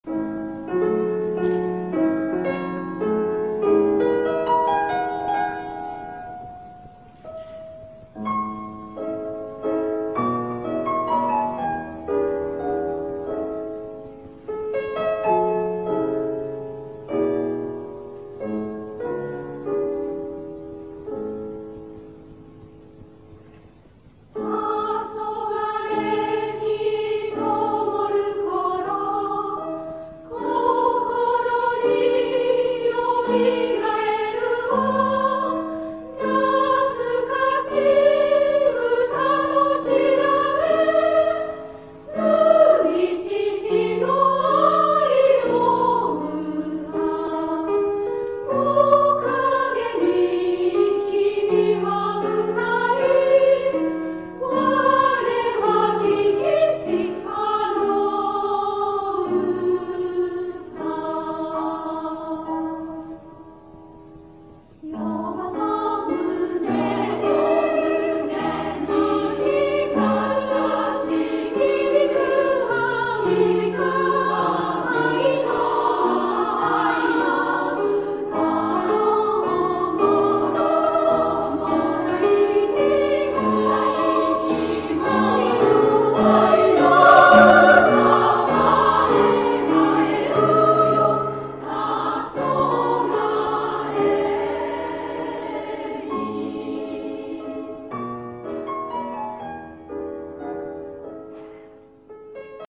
女声合唱団「道」 はじめてのコンサート
女声合唱団「道」のはじめてのコンサートを平成６年４月２４日（日）に開きました。